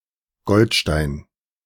Eugen Goldstein (/ˈɡldstn/; German: [ˈɡoːltʃtaɪn]